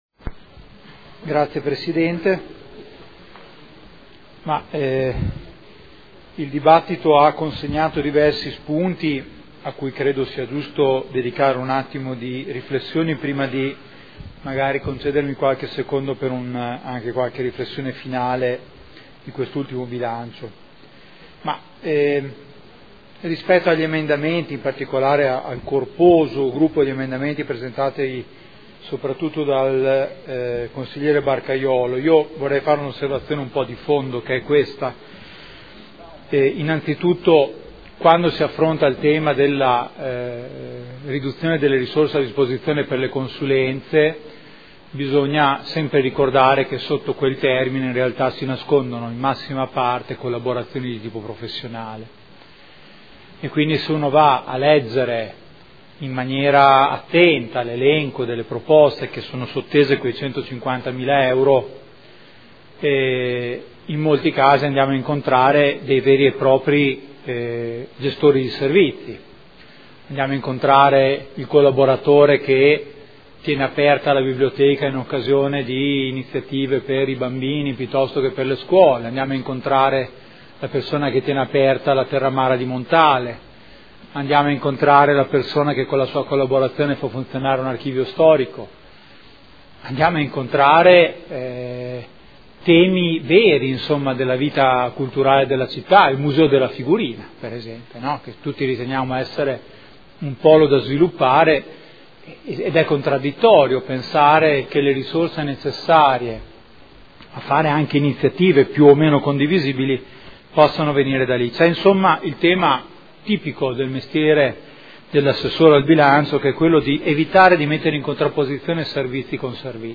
Seduta del 13 marzo.